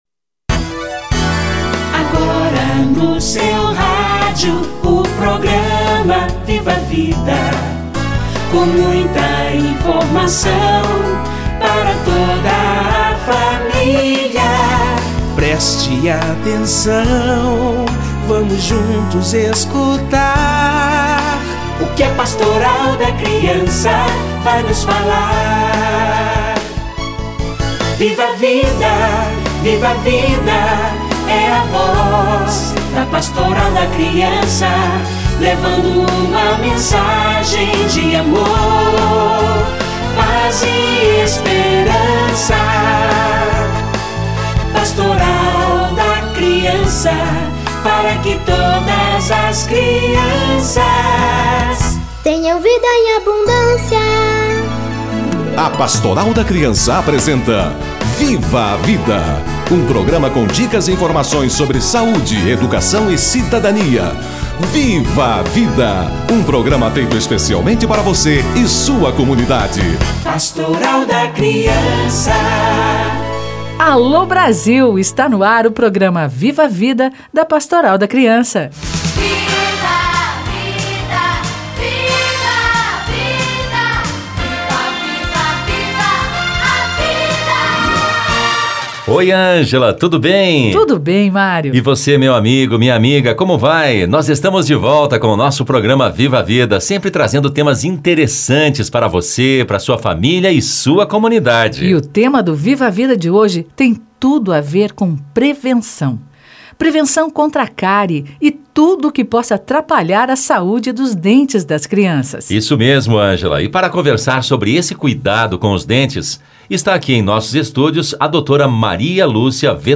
Saúde bucal da criança - Entrevista